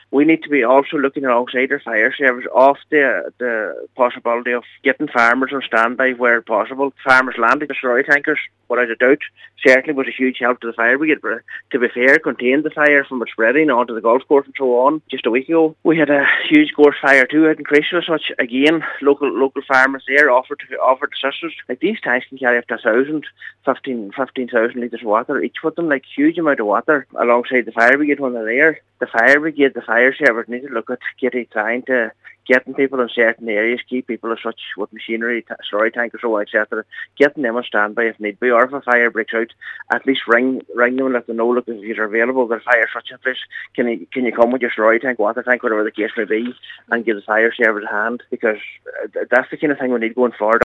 Cllr Michael McClafferty says the move would be beneficial for the fire services during warm weather: